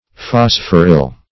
Search Result for " phosphoryl" : The Collaborative International Dictionary of English v.0.48: Phosphoryl \Phos"phor*yl\ (f[o^]s"f[o^]r*[i^]l), n. [Phosphorus + -yl.]
phosphoryl.mp3